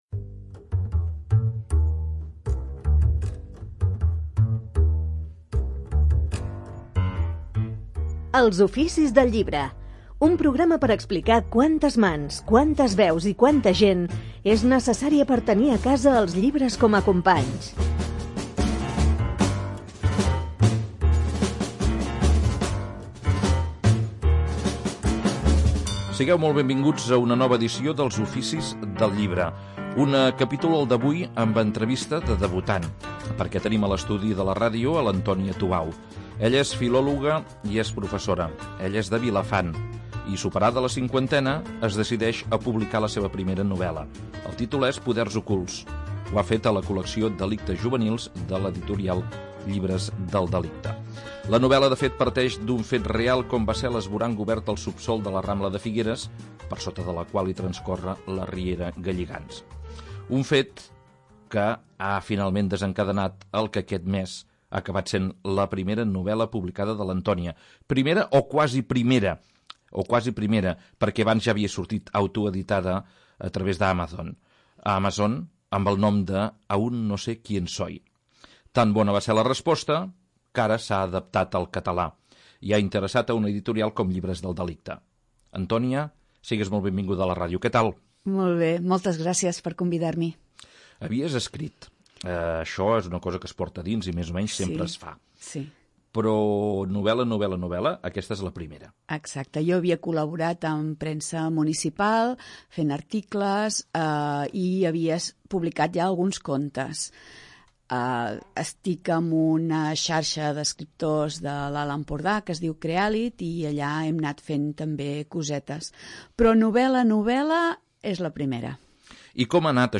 Als Oficis… d’avui, una entrevista de debutant.